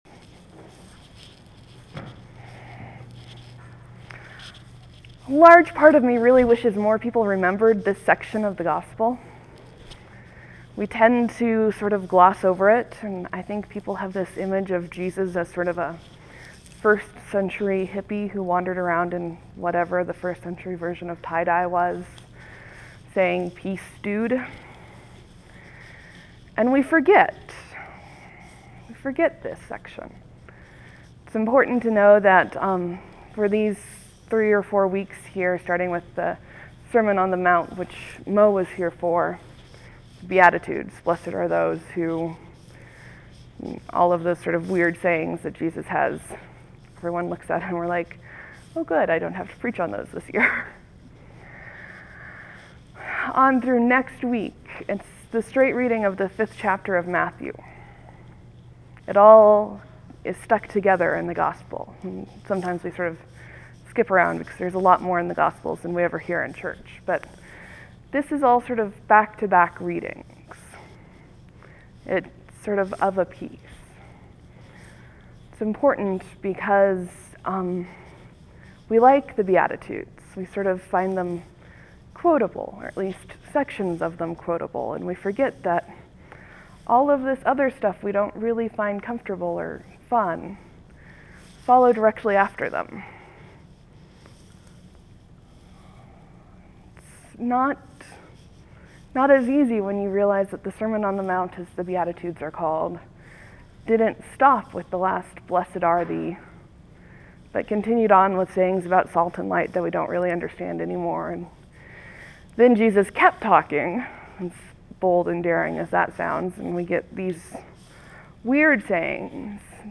(There will be a few moments of silence before the sermon begins. Thanks for your patience.)